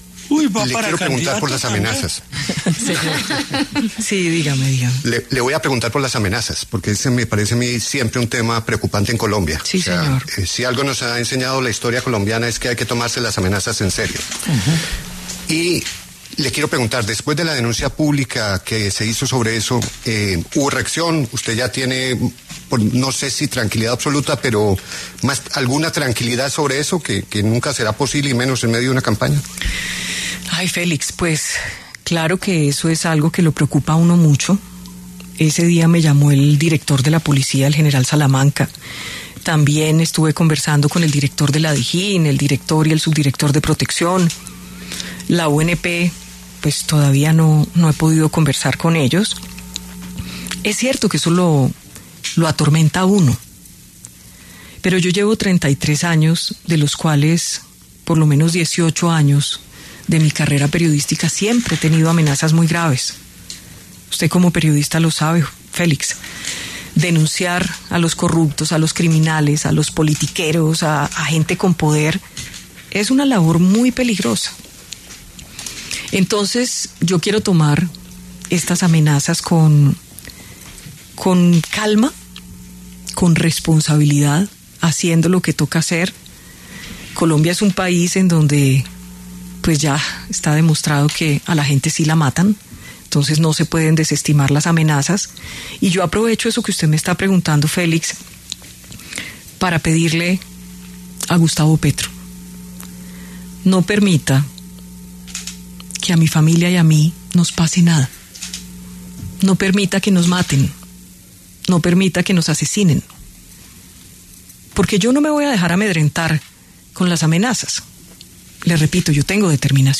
Victoria Eugenia Dávila Hoyos, más conocida como Vicky Dávila, pasó por los micrófonos de La W, con Julio Sánchez Cristo, para hablar sobre su precandidatura presidencial para 2026.